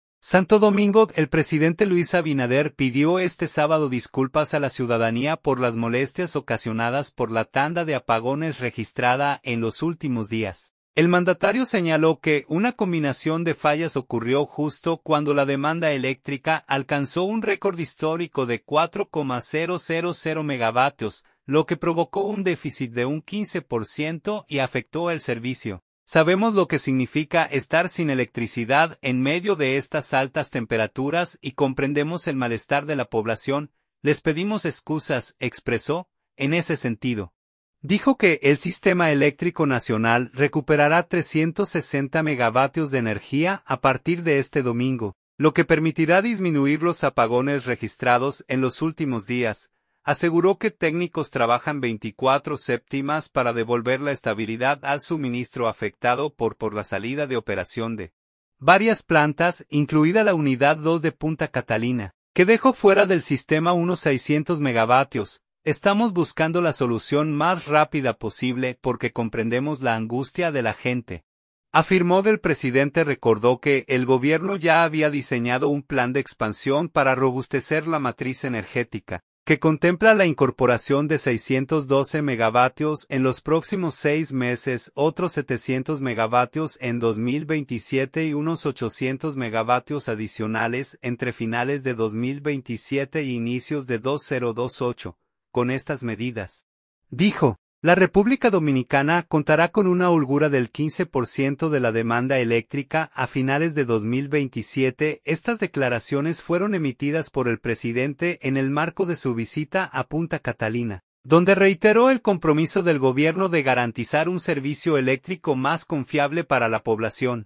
Estas declaraciones fueron emitidas por el presidente en el marco de su visita a Punta Catalina, donde reiteró el compromiso del Gobierno de garantizar un servicio eléctrico más confiable para la población.